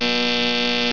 BUZZ1.WAV